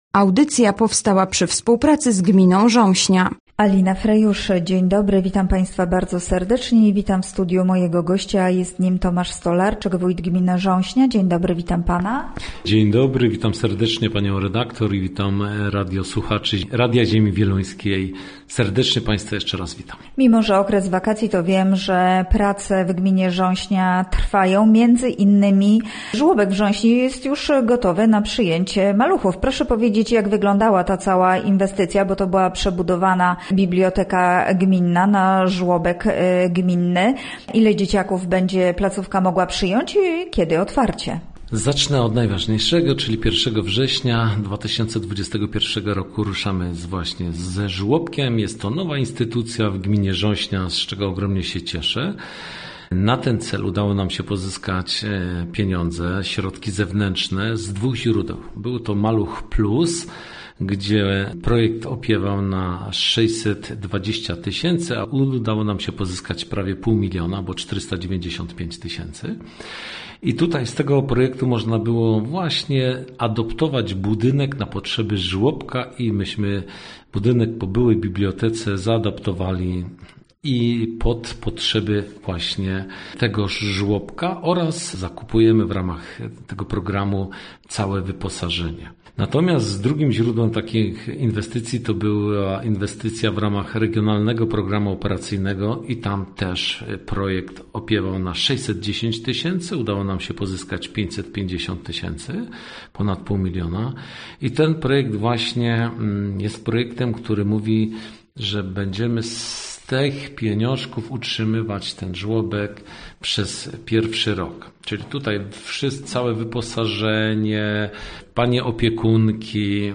Gościem Radia ZW był Tomasz Stolarczyk, wójt gminy Rząśnia